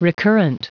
Prononciation du mot recurrent en anglais (fichier audio)
Prononciation du mot : recurrent